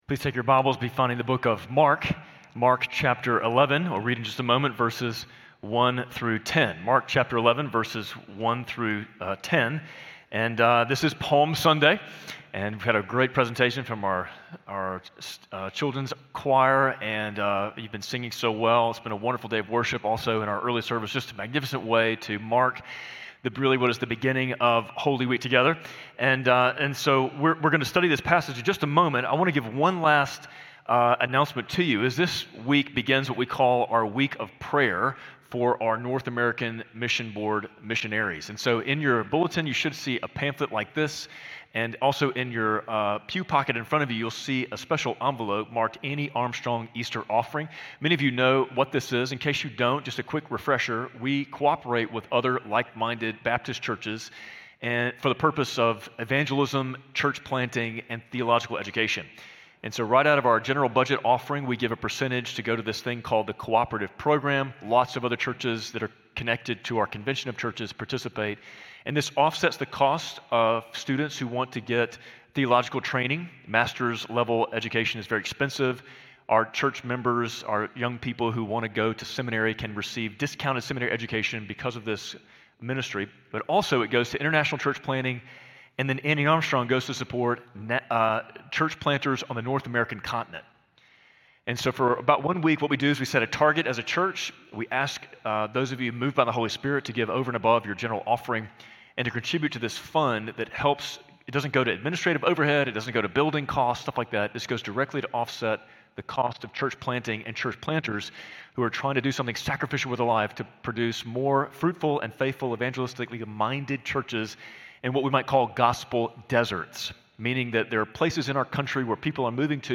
Spring Hill Baptist Sunday Sermons (Audio) / Journey to Easter: Palm Sunday - The King is Coming for Me